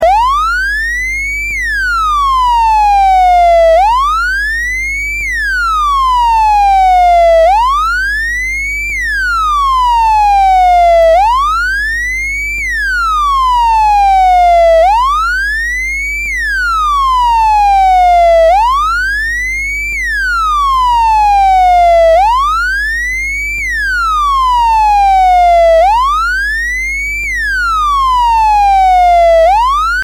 This article gives seven examples of sounds you can produce with Sound Lab, the simple single-board analogue synthesiser that I described in the earlier article Sound Lab - a Simple Analogue Synthesiser:
Police car siren
This uses the Envelope Generator module with a long Attack and Decay to modulate the Oscillator module.
siren.mp3